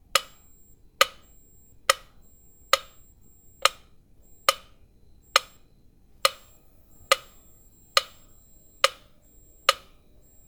Tiempo larguetto en un metrónomo
metrónomo